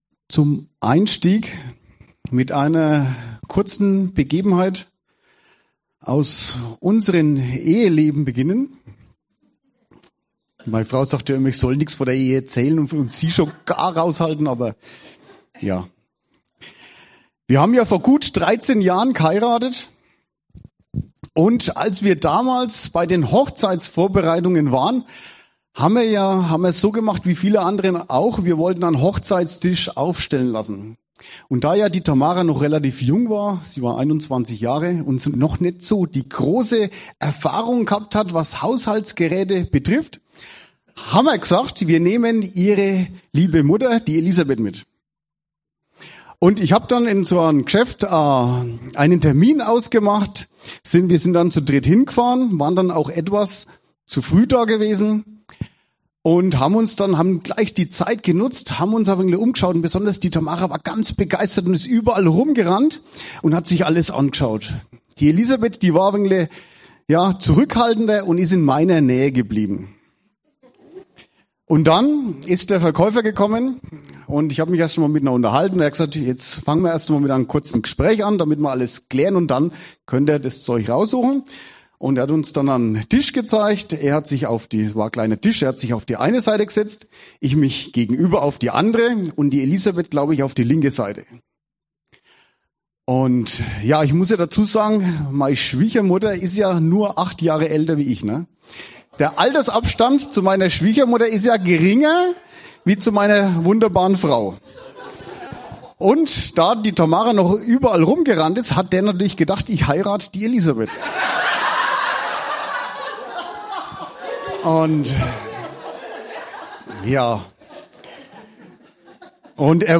Die Predigt reflektiert das Verhältnis zwischen Braut und Bräutigam in biblischer Perspektive. Die Rede von Braut und Bräutigam wird auf die Ehe zwischen Mann und Frau angewendet. Der Prediger betont, dass die Ehe ein wunderbares Geschenk Gottes ist, das auch Herausforderungen und Schwierigkeiten mit sich bringt.